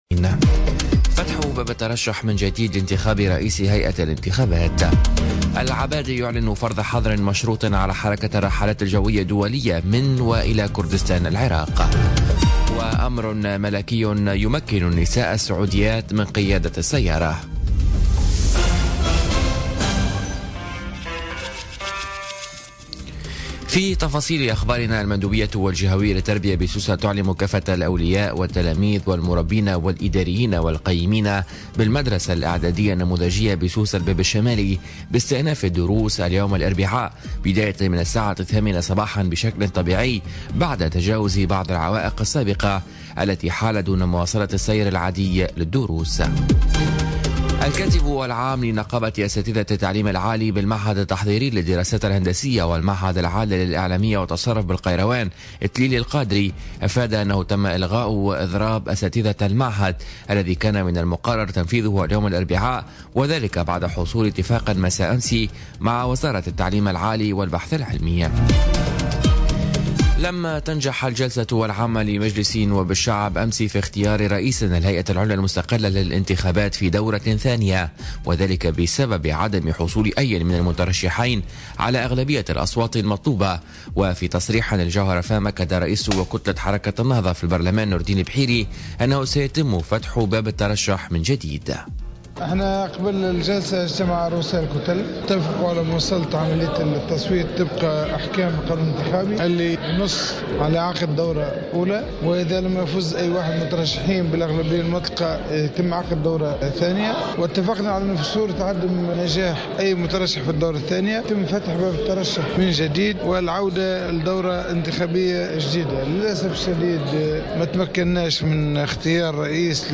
نشرة أخبار السابعة صباحا ليوم الاربعاء 27 سبتمبر 2017